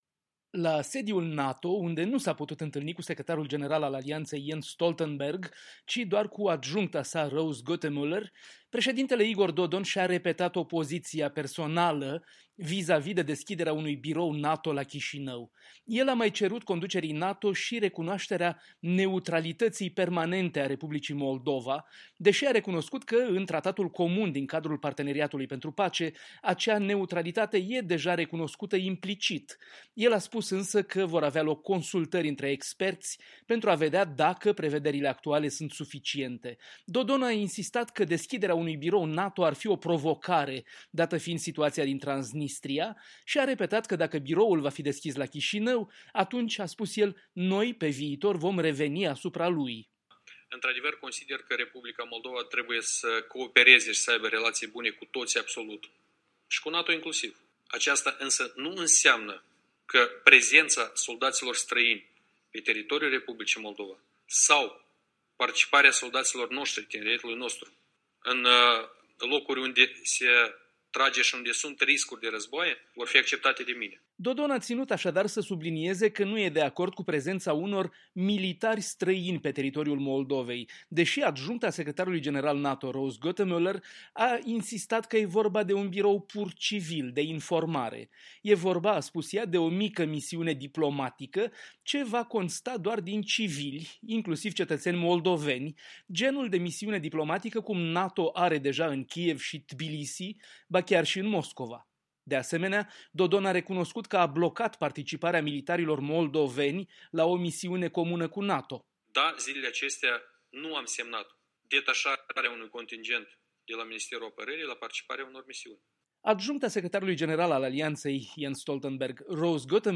Conferința de presă comună de la Bruxelles a președintelui Moldovei cu secretarul general adjunct al NATO Rose Gottemoeller.